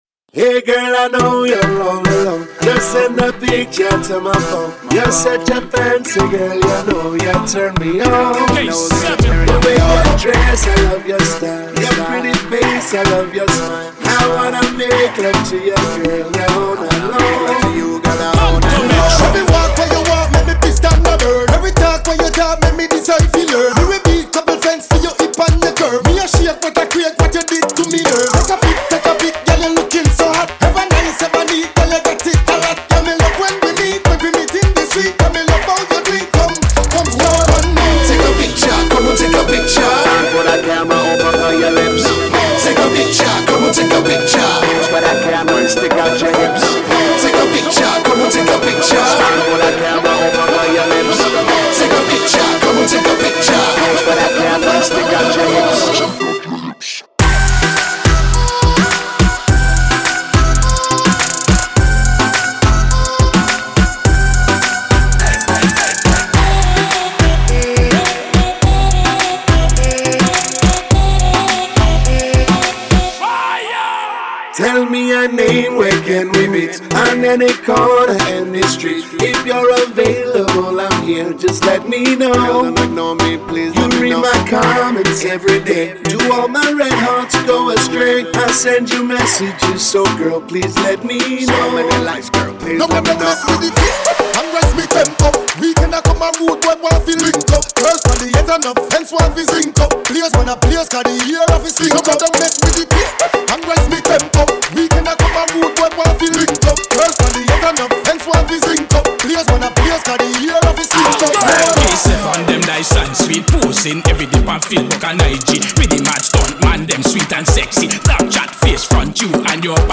это яркая и ритмичная композиция в жанре хип-хоп и регги